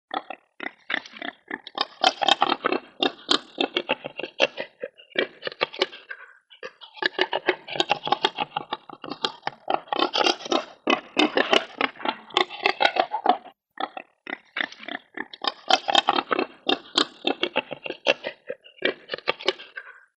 На этой странице собраны натуральные звуки диких кабанов: от хрюканья и рычания до топота копыт по лесу.
Звук рохкання дикого кабана